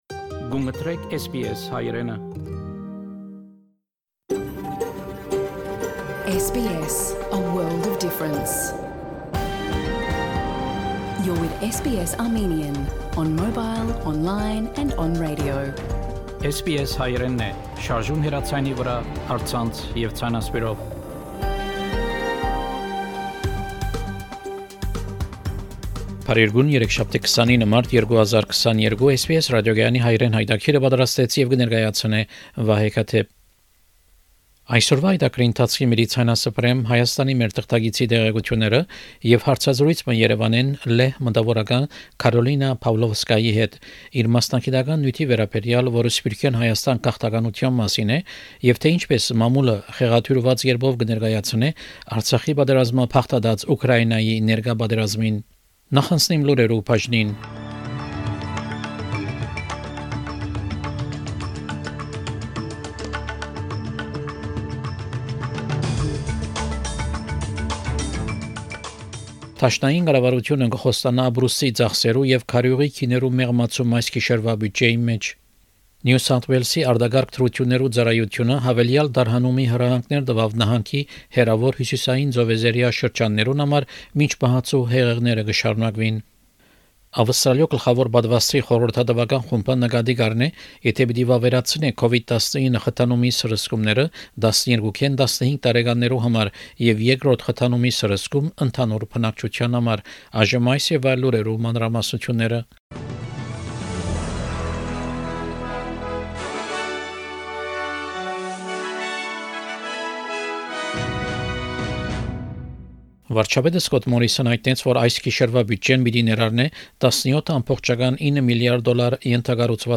SBS Armenian news bulletin from 29 March 2022 program.